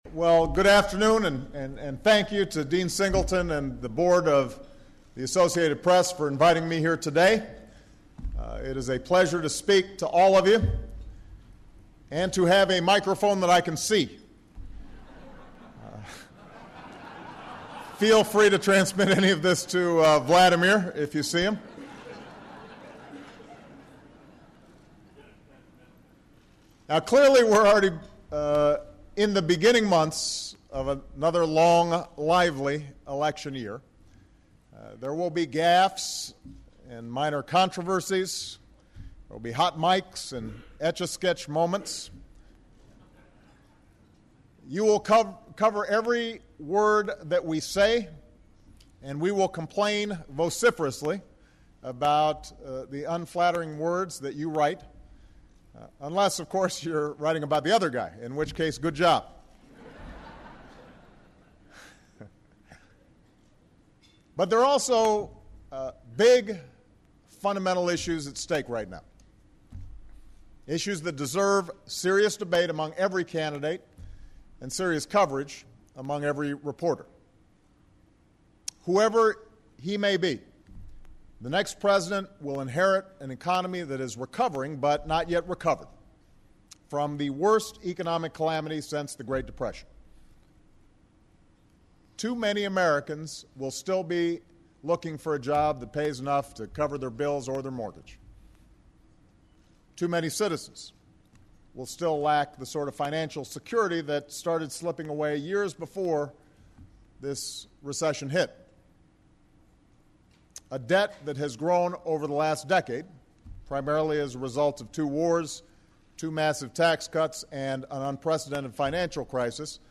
U.S. President Barack Obama speaks at the Associated Press luncheon held in Washington, D.C